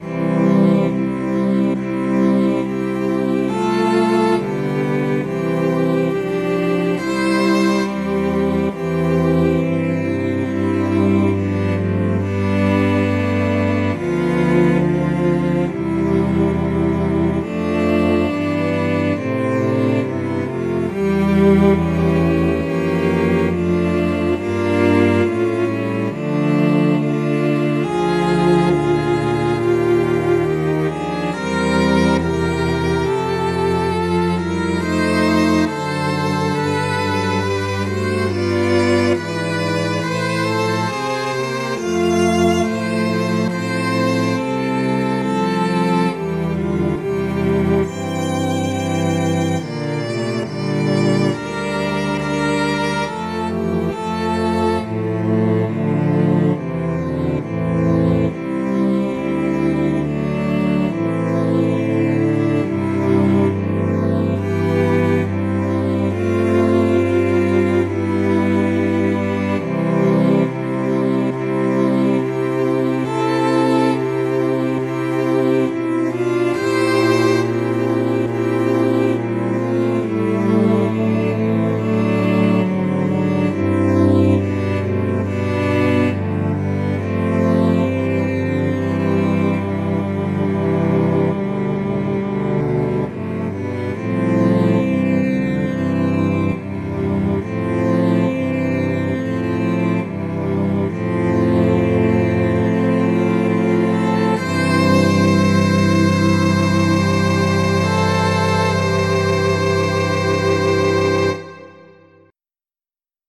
für vier Celli